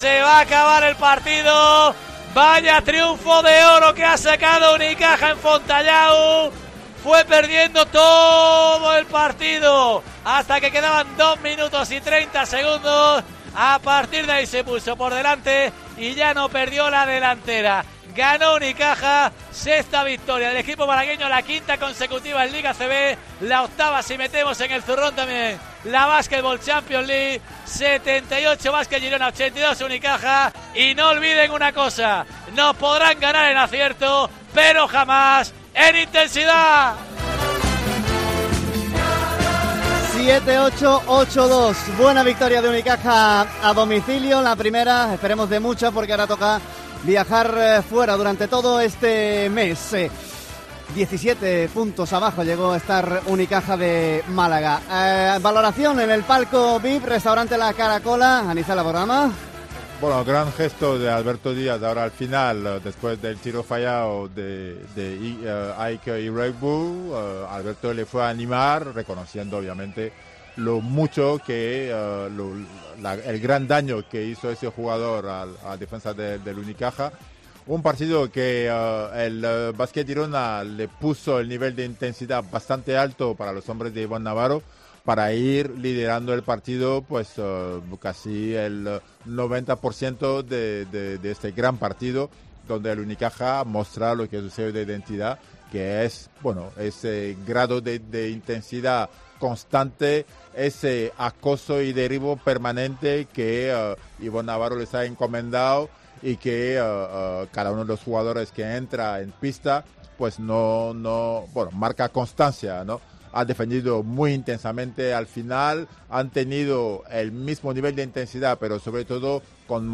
Así te narramos en COPE Málaga la victoria de Unicaja en Girona (78-82)